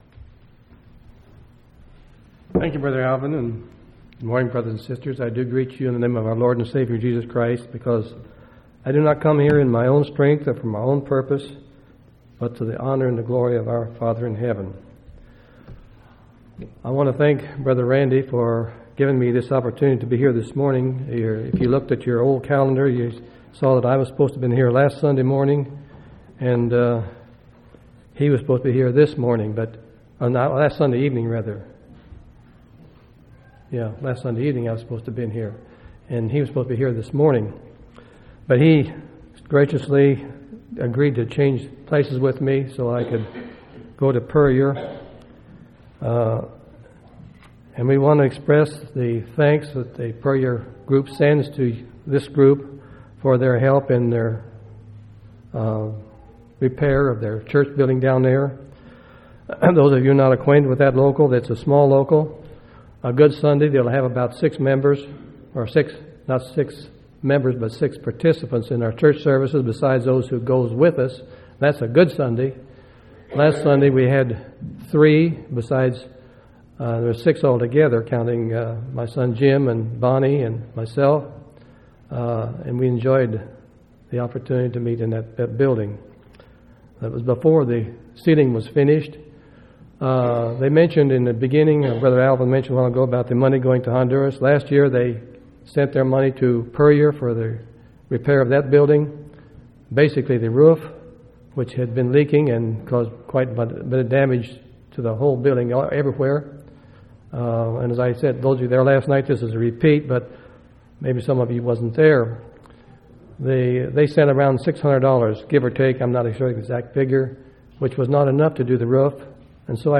10/31/2004 Location: Temple Lot Local Event